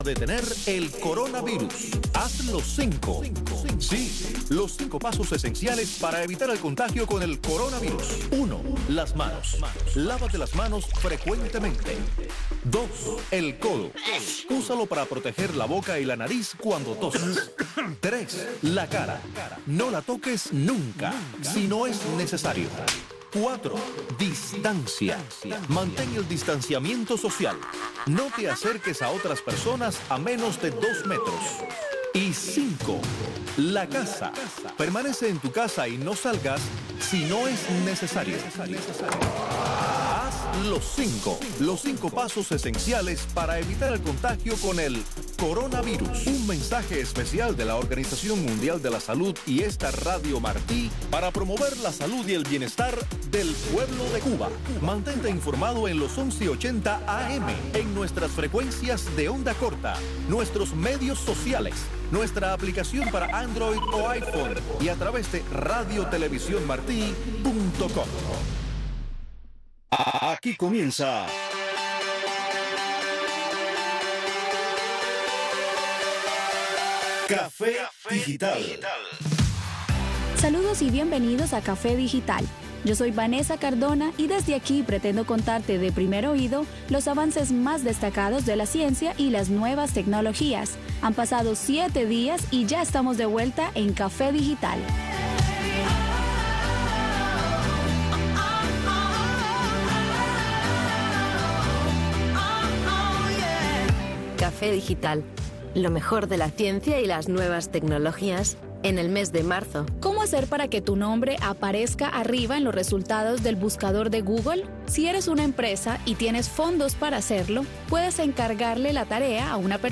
Café digital es un espacio radial que pretende ir más allá del solo objetivo de informar sobre nuevos avances de la ciencia y la tecnología. Pretendemos crear un intercambio interactivo entre el programa y su audiencia a través de las redes sociales, tratando de involucrar al oyente joven en Cuba hasta lograr que se sienta parte del proyecto. Café digital traerá invitados que formen parte de la avanzada científica y tecnológica en el mundo y promoverá iniciativas e ideas que puedan llevar a cabo los jóvenes dentro de Cuba para dar solución a sus necesidades más cotidianas.